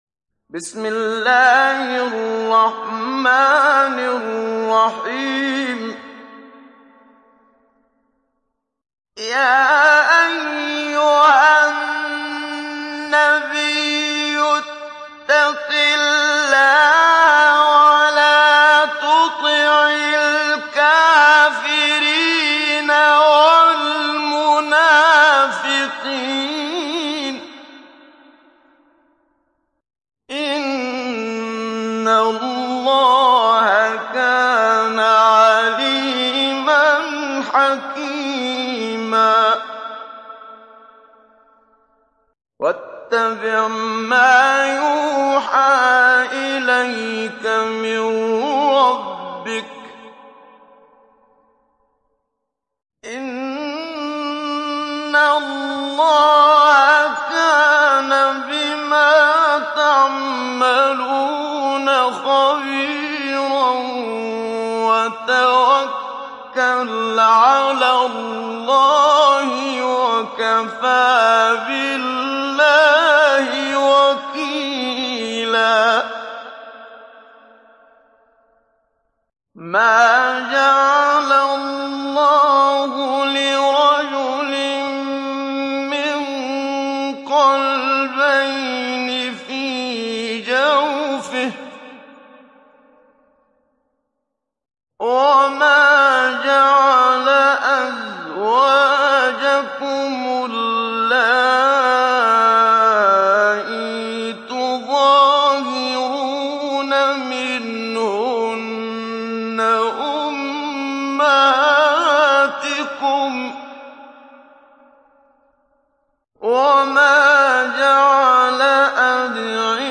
Download Surah Al Ahzab Muhammad Siddiq Minshawi Mujawwad